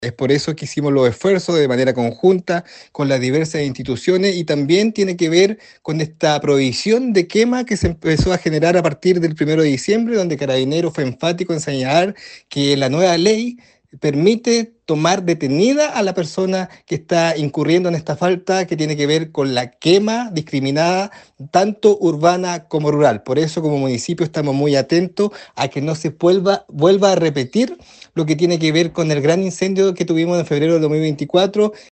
El evento, que se llevó a cabo en el frontis de la Municipalidad tuvo como objetivo, sensibilizar a los habitantes sobre la importancia de prevenir incendios forestales y fortalecer las medidas de seguridad en esta temporada de altas temperaturas y sequía.
Durante la actividad, el alcalde Carrillo destacó la relevancia de la colaboración entre las instituciones y la comunidad y señalo: Esta campaña busca generar conciencia sobre el impacto devastador de los incendios forestales y la importancia de la prevención.
26-diciembre-24-Marco-Carrillo-Incendios.mp3